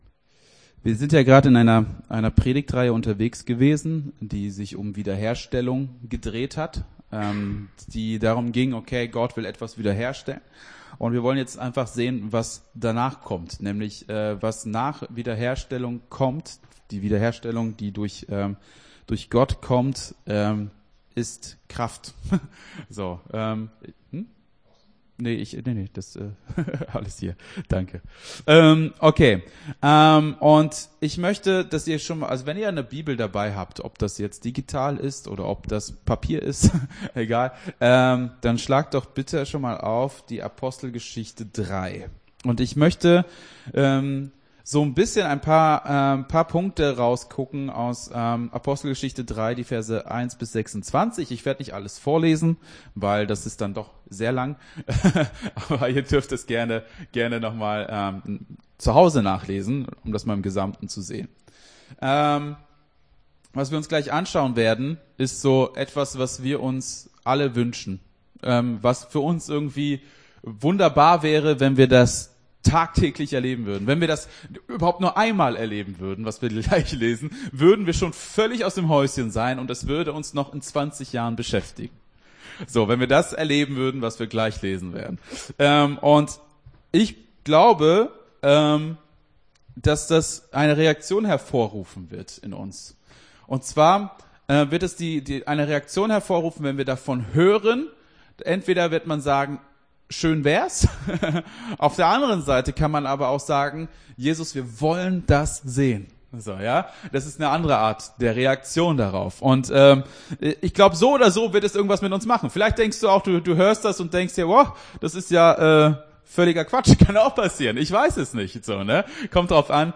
Gottesdienst 27.02.22 - FCG Hagen